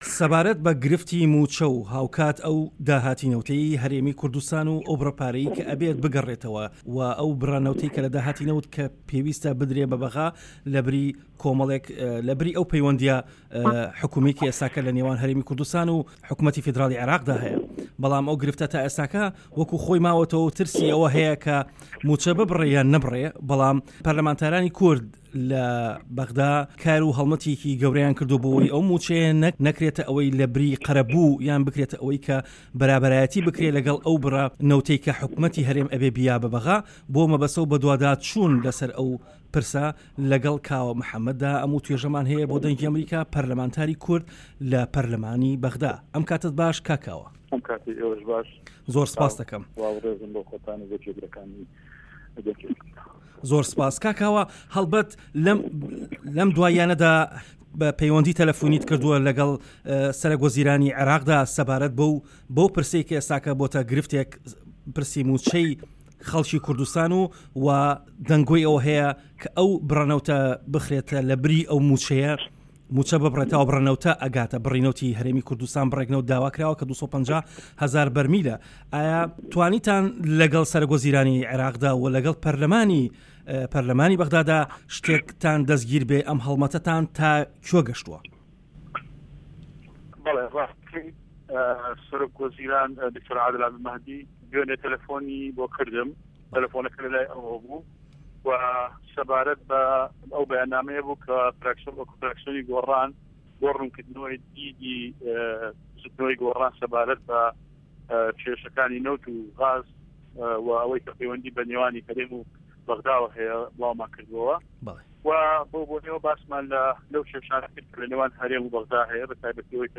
وتووێژ لەگەڵ کاوە محەمەد